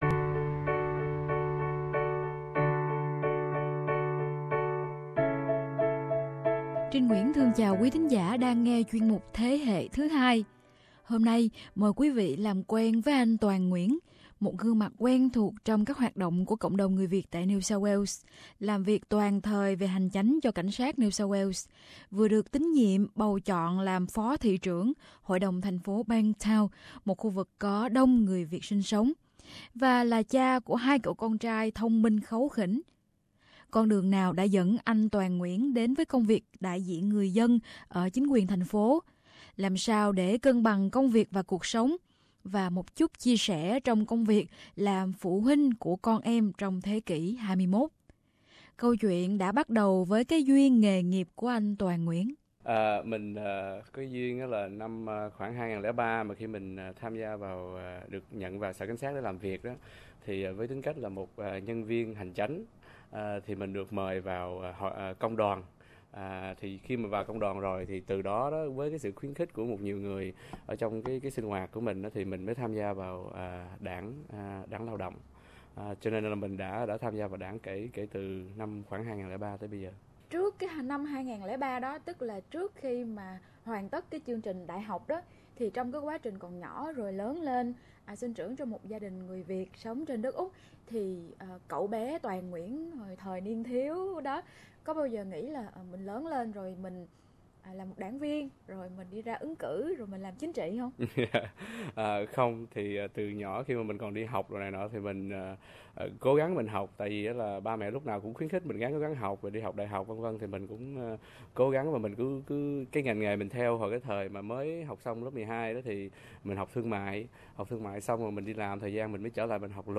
Trò chuyện với Toàn Nguyễn, Phó Thị Trưởng Hội đồng Thành phố Bankstown về duyên nghề nghiệp, và chút chia sẻ trong vai trò làm phụ huynh của trẻ em thế kỷ 21.